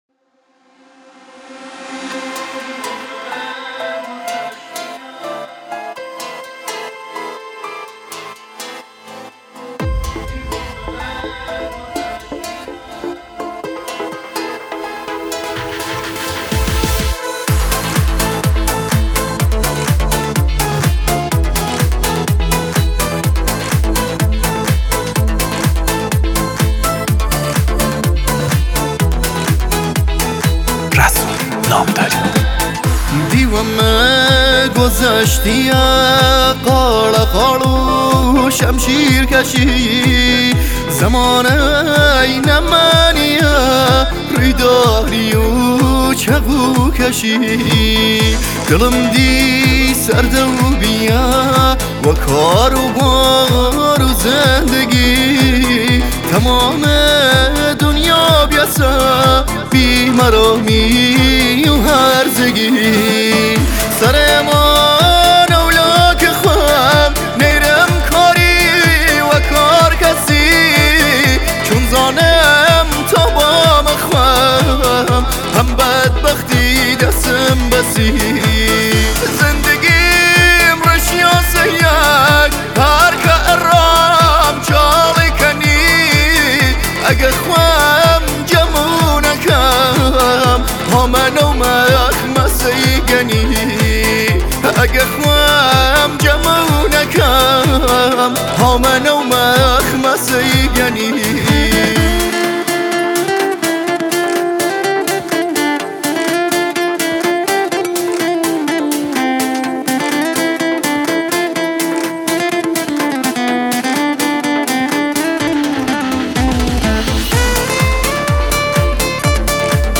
آهنگ کوردی
آهنگ غمگین آهنگ محلی